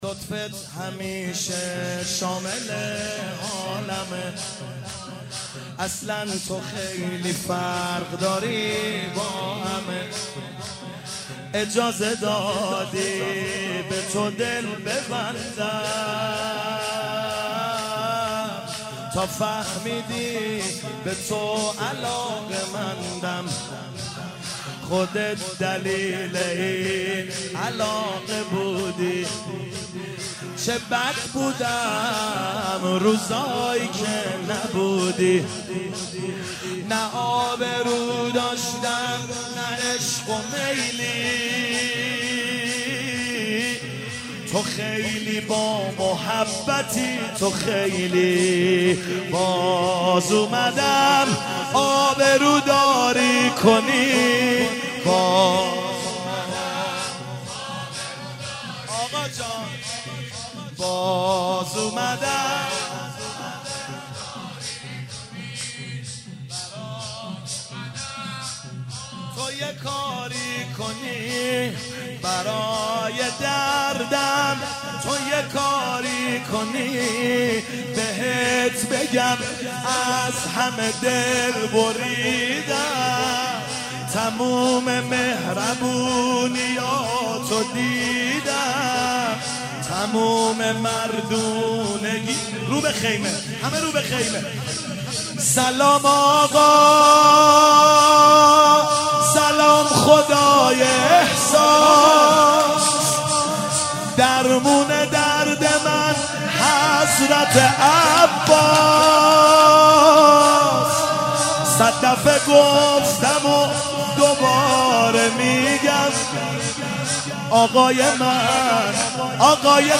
شب شام غریبان محرم 97 - شور - لطفت همیشه شامل منه